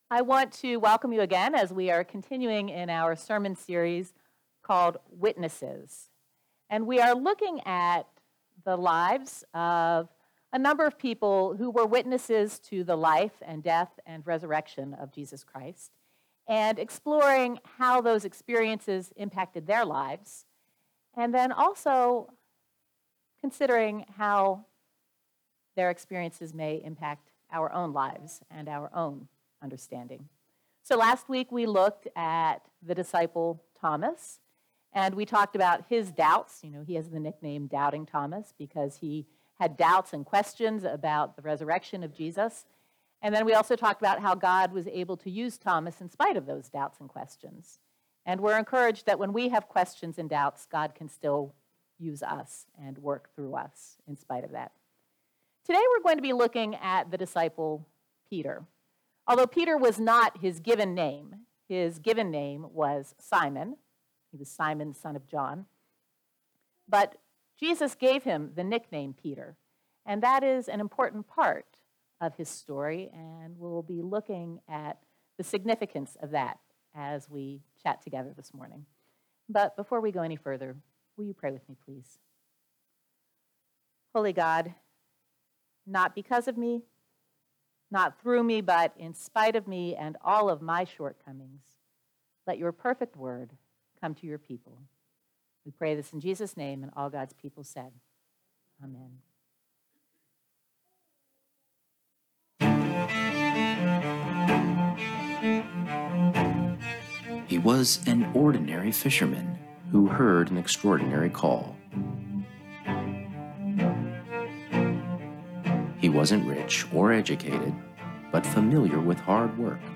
Morrisville United Methodist Church Sermons